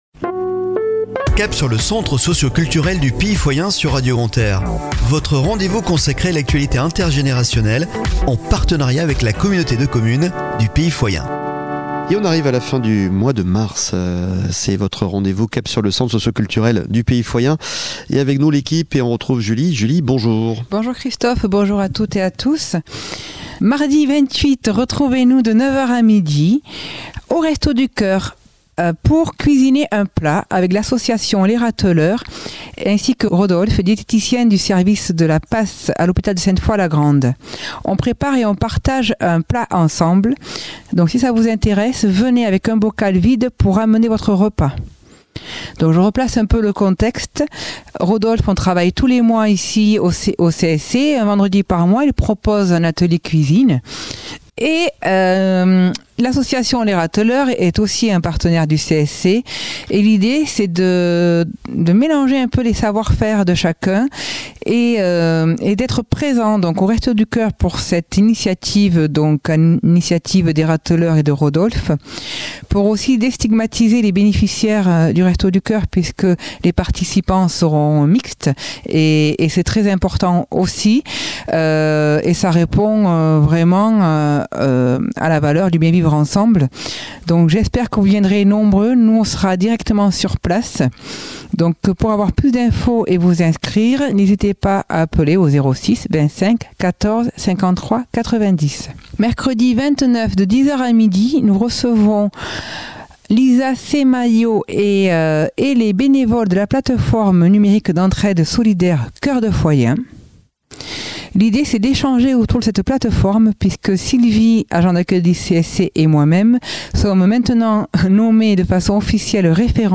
Chronique de la semaine du 27 Mars au 02 Avril 2023 !